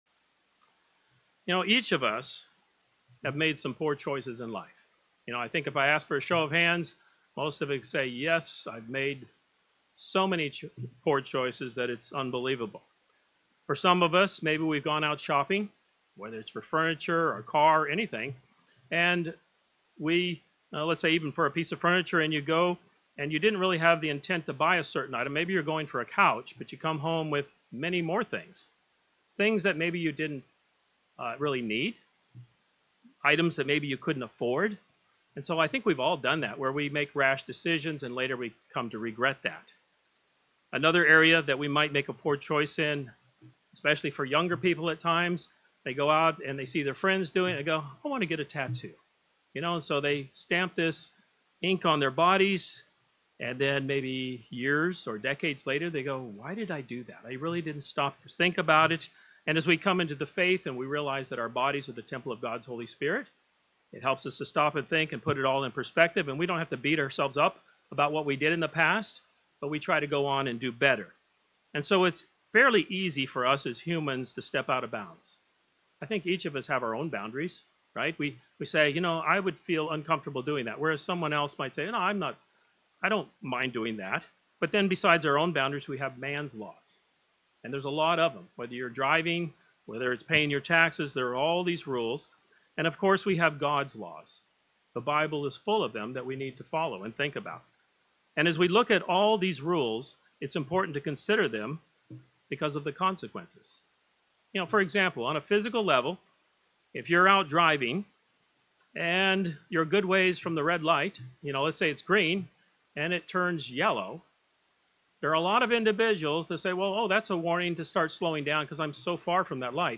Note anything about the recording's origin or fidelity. Given in Tampa, FL